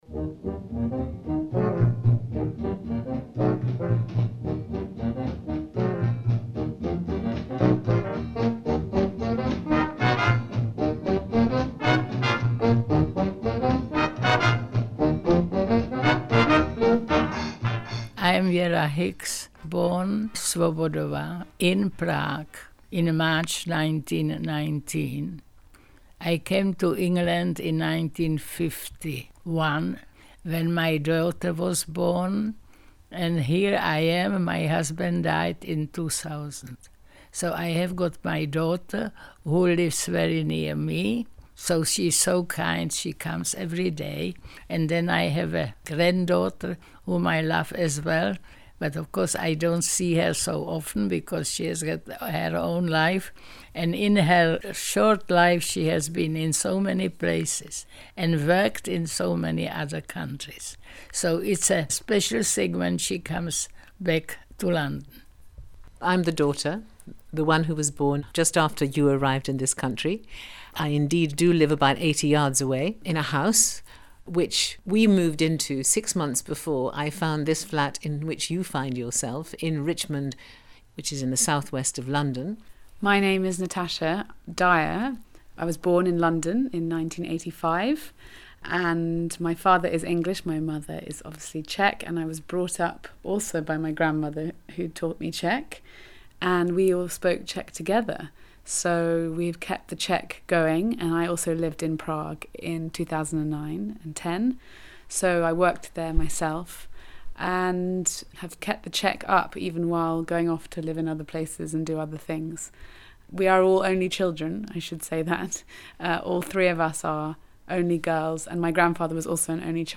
When I arrived at the flat, all three were busy mixing ingredients to make “vanilkové rohlíčky”, perhaps the most typical of Czech Christmas biscuits, and before long, they broke into song…